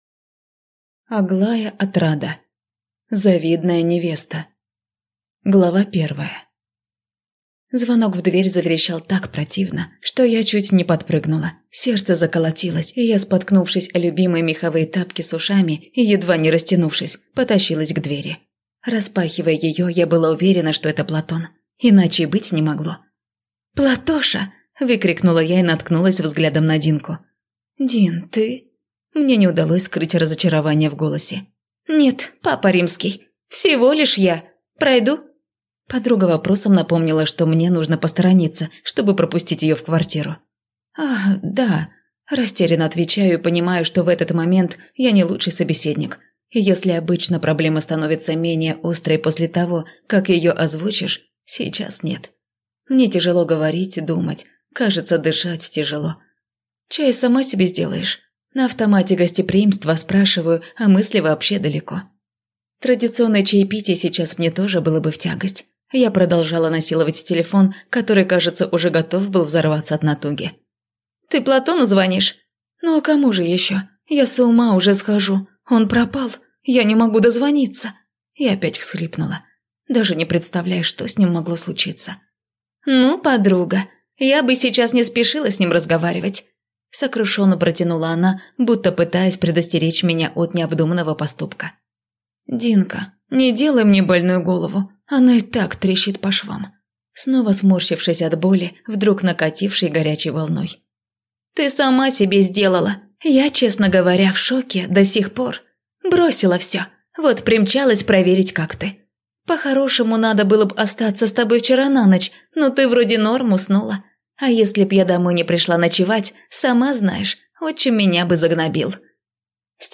Аудиокнига Завидная невеста | Библиотека аудиокниг